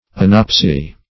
Meaning of anopsy. anopsy synonyms, pronunciation, spelling and more from Free Dictionary.